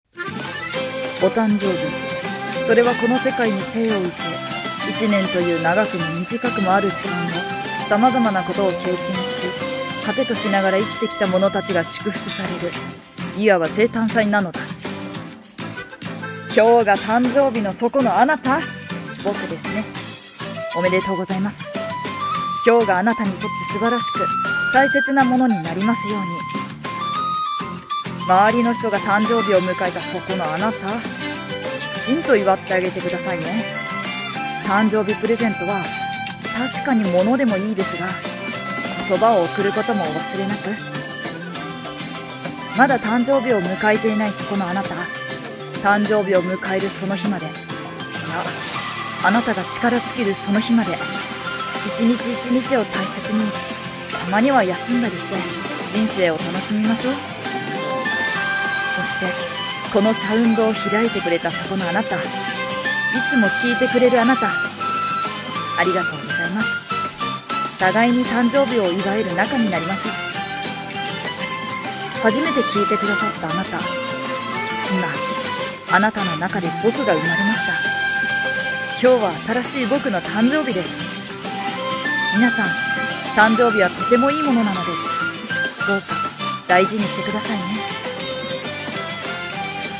】【一人声劇】「誕生日！」